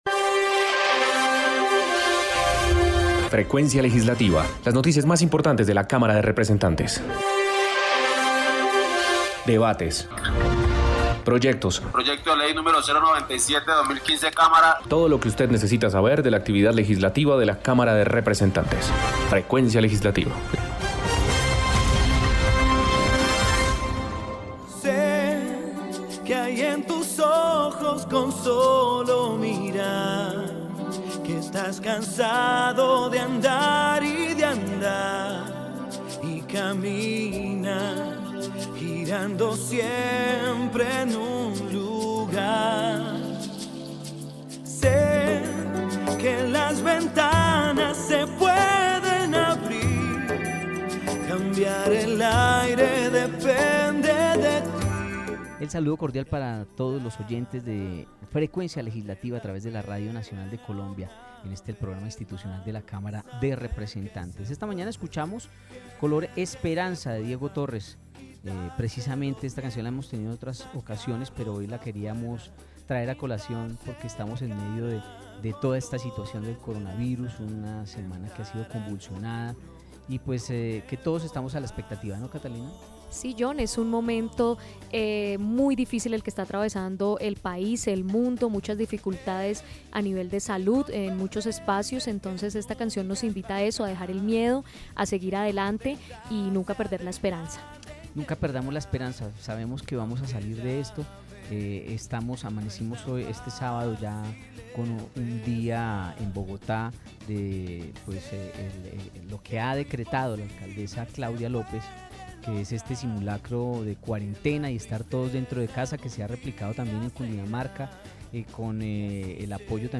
Programa Radial Frecuencia Legislativa Sábado 21 de marzo de 2020